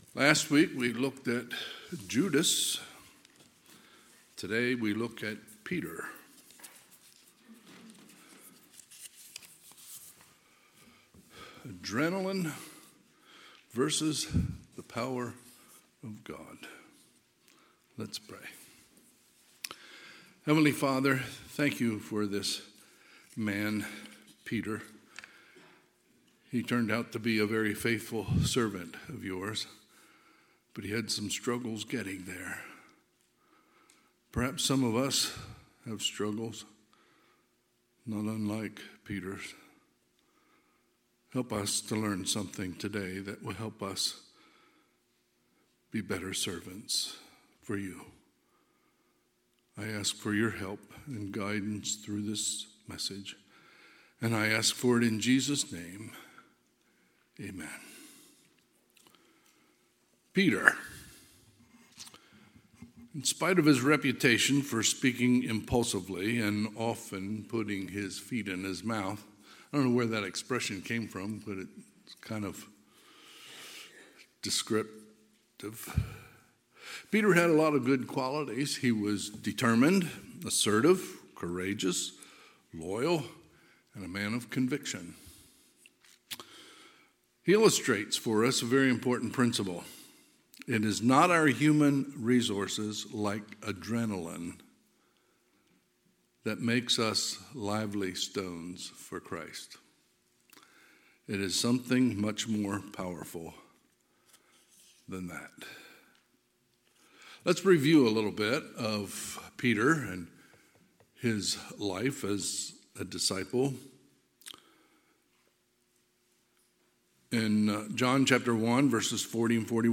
Sunday, March 17, 2023 – Sunday AM
Sermons